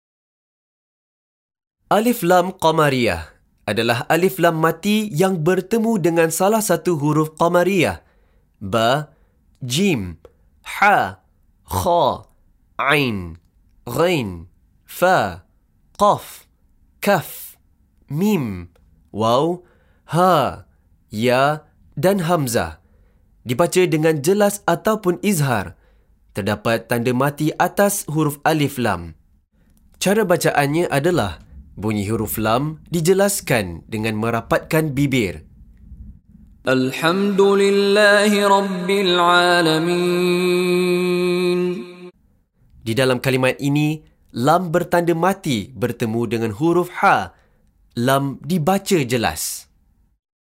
Penerangan Hukum + Contoh Bacaan dari Sheikh Mishary Rashid Al-Afasy
Bunyi huruf Lam disebut dengan JELAS tanpa dengung